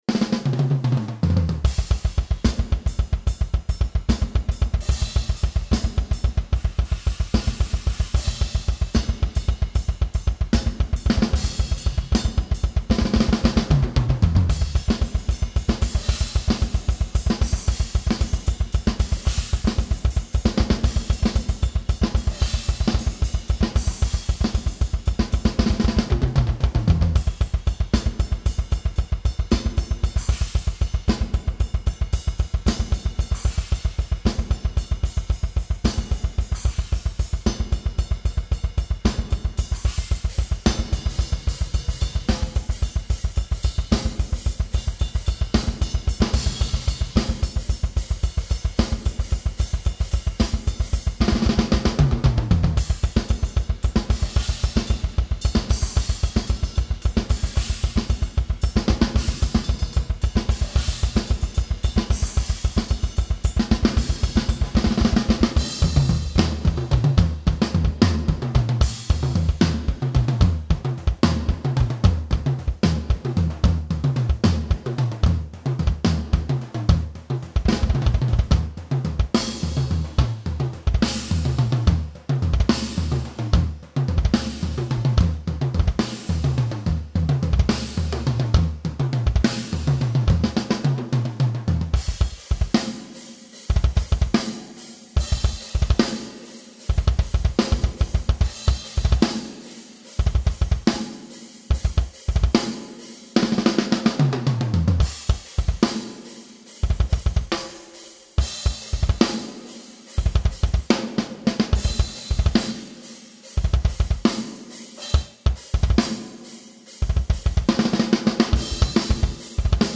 Trumtestet.mp3